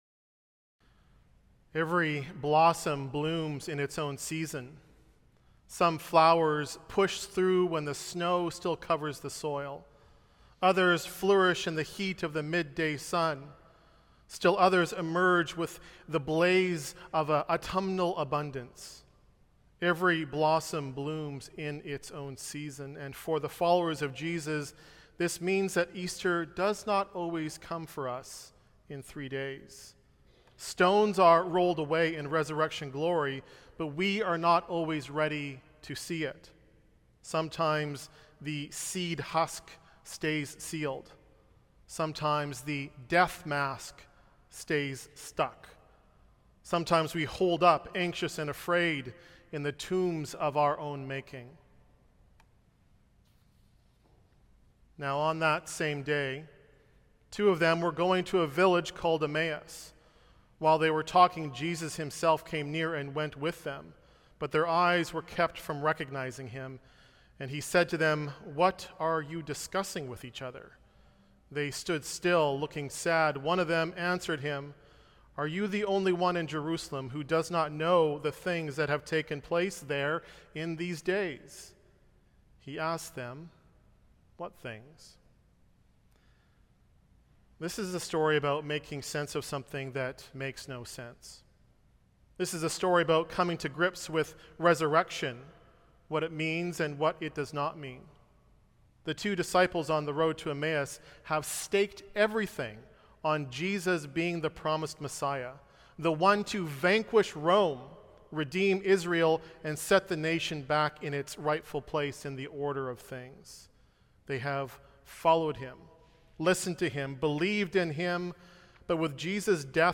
Earth Day Service
Sermon Notes